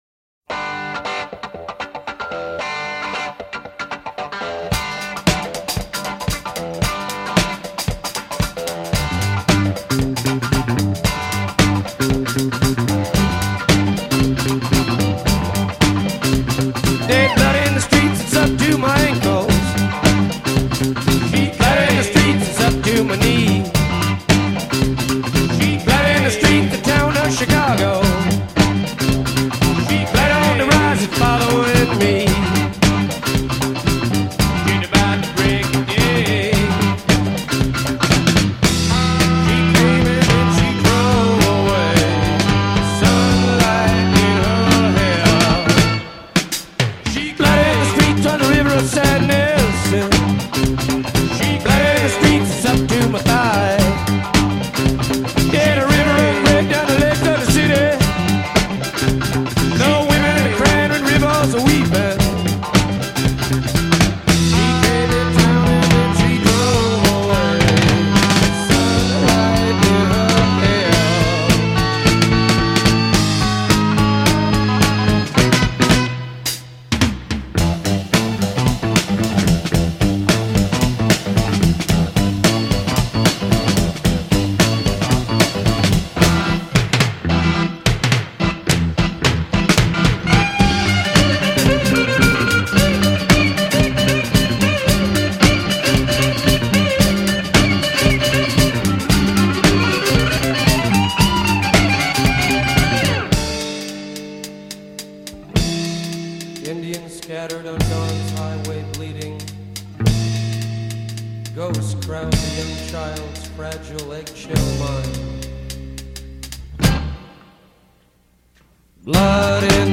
Intro 2 gtr  + 2 batt + 2 bass + 2 clav
Couplet 1 avec Choeurs : She Plays
• 6 mesure solo quitare (sur meme theme que couplet)
• Voix + bat seules, 6 mesure + 1 break (D7)